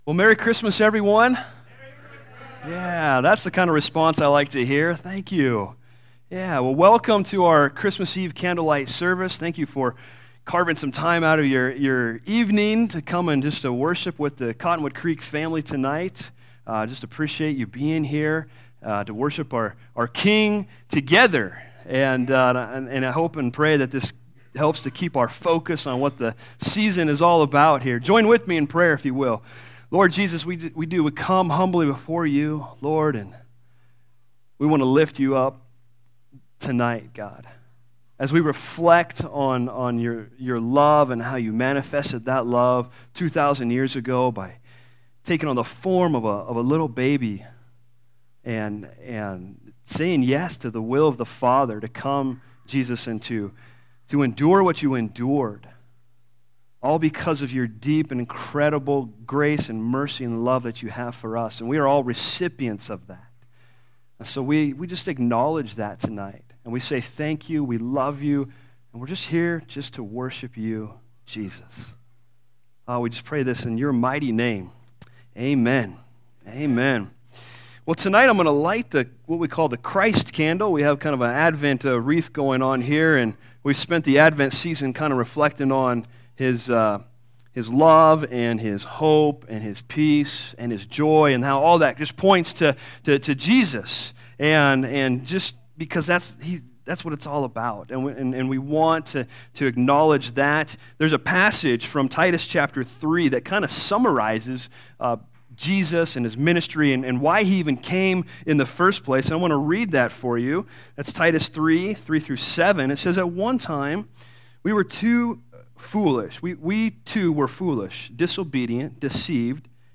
Christmas Eve Candlelight Service 2018